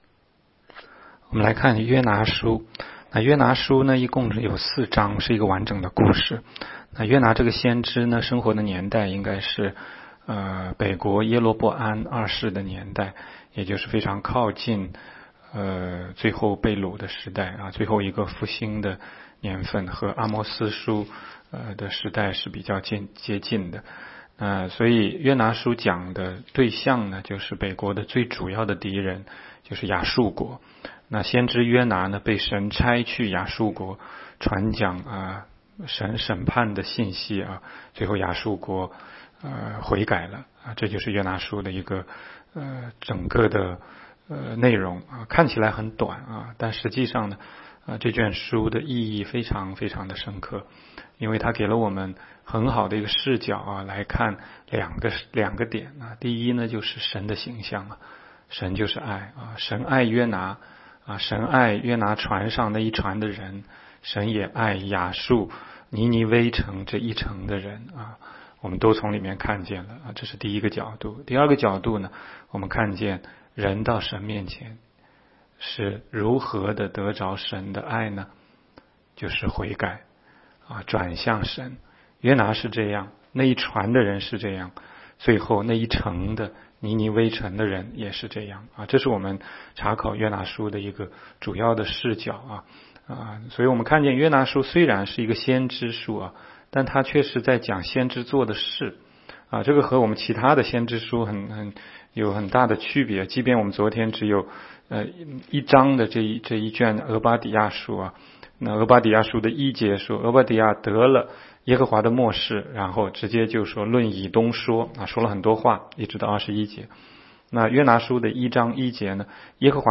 16街讲道录音 - 每日读经 -《约拿书》1章